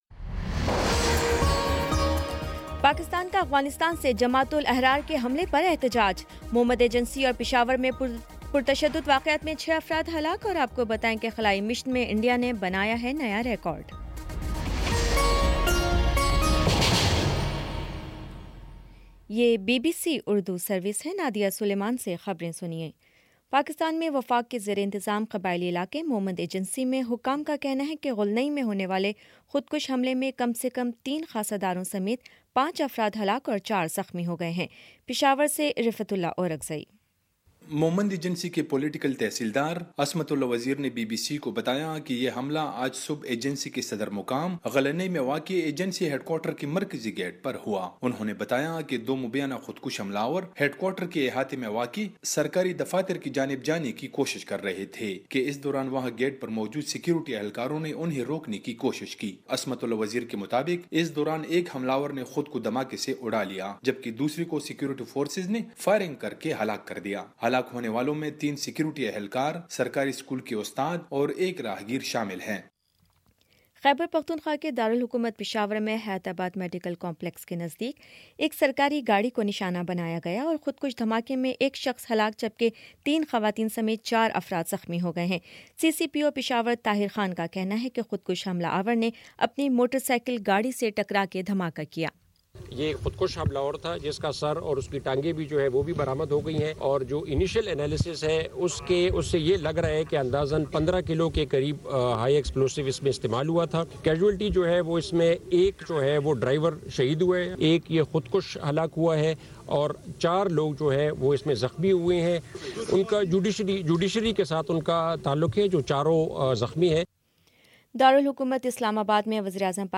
فروری 15 : شام سات بجے کا نیوز بُلیٹن